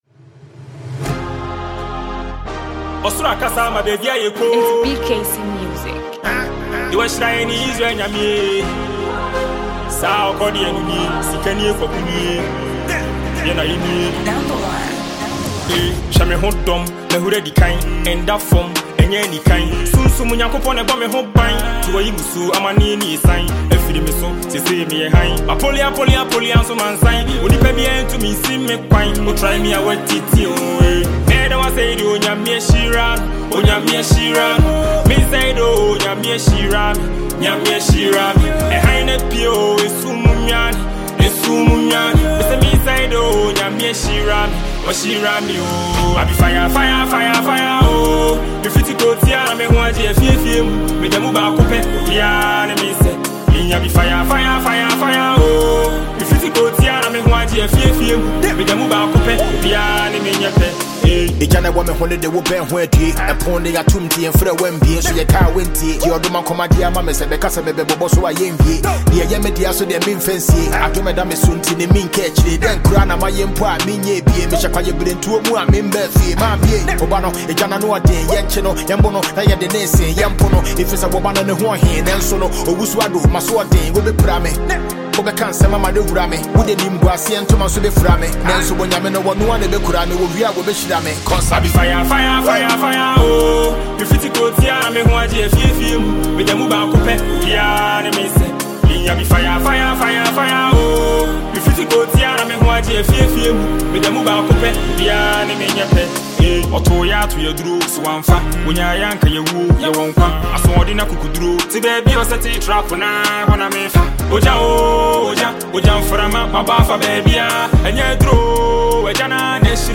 Is a beautiful flow
dope tune and million dollar rap